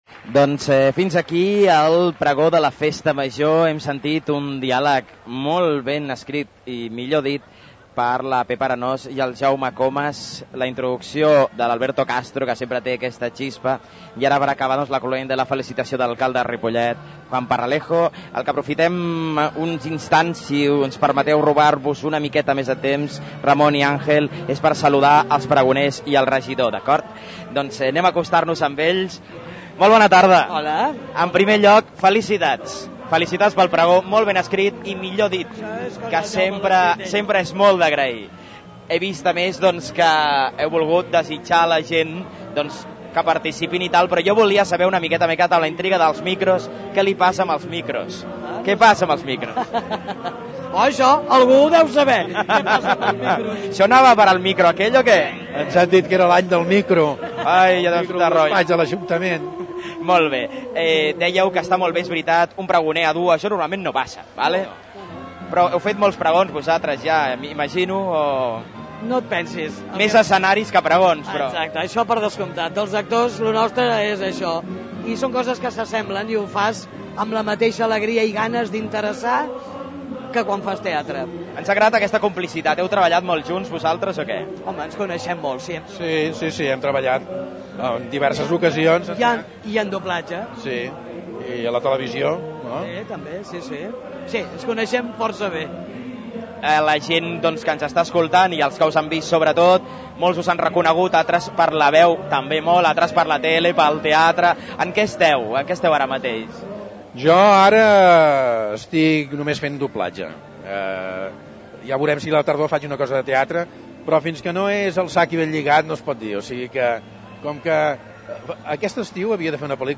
Prego Festa Major2006(Part2).mp3